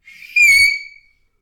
sounds_chalk_screech_02.ogg